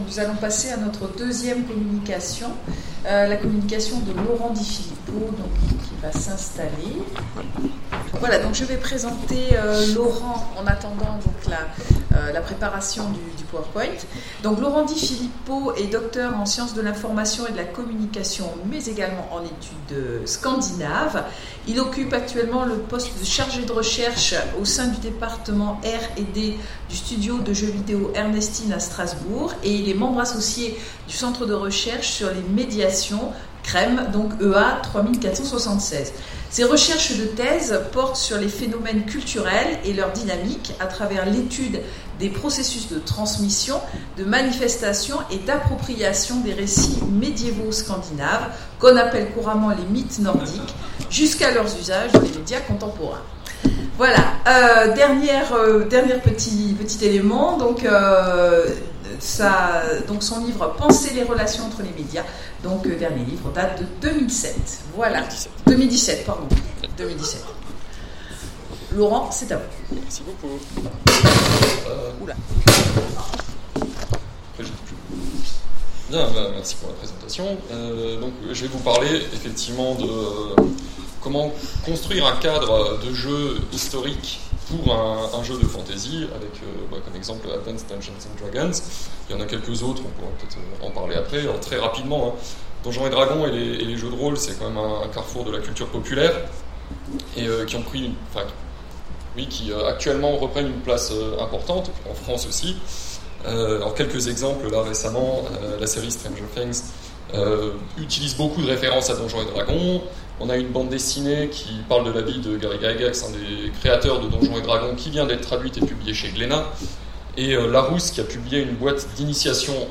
Colloque universitaire 2018 : La mise en scène ludique de l’Histoire